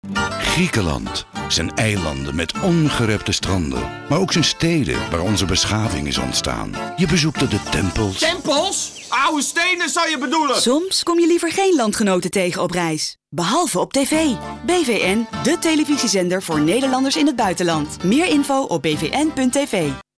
De nieuwe campagne voor BVN Nederland, die bestaat uit 1 TV-spot en 3 radiospots, toont perfect aan dat Nederlanders liever geen landgenoten zien in het buitenland ... behalve op TV via BVN.